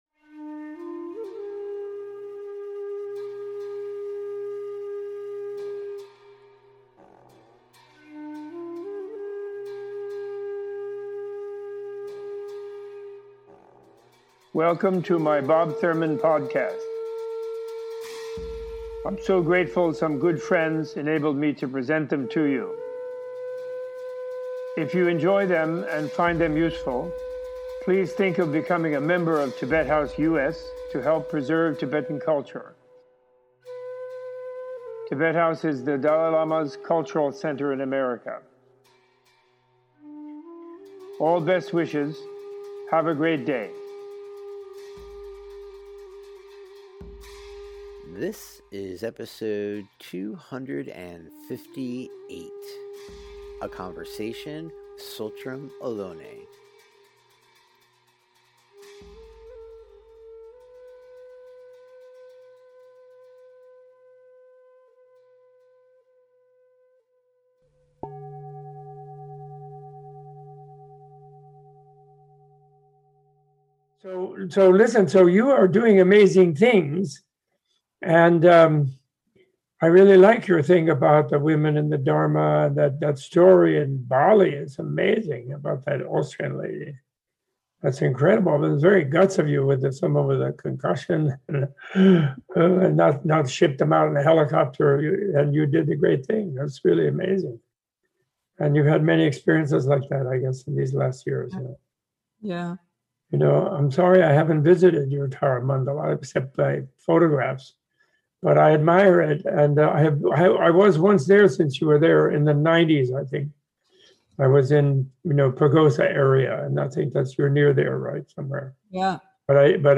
Robert Thurman welcomes Dharma pioneer, Author and founder of The Tara Mandala Retreat center Tsultrim Allione for an intimate and thought provoking conversation about their experiences studying, learning and teaching Buddhism.